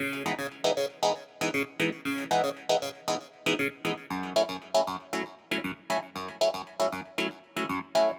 04 Clavinet PT2.wav